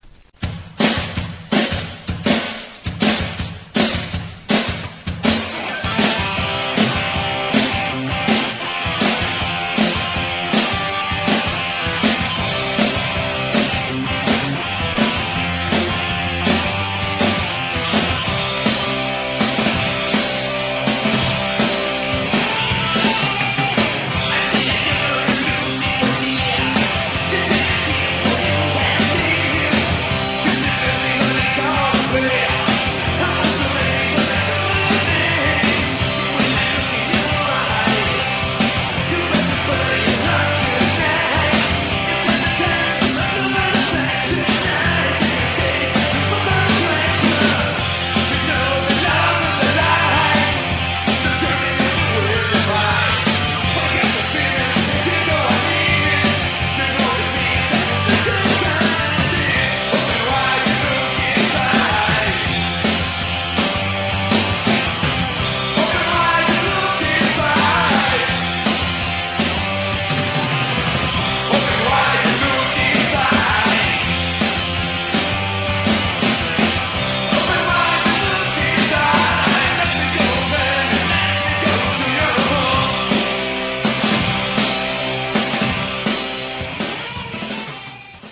Third World Glam Rock Band